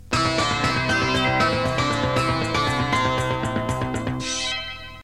Senyal desconnexió publicitària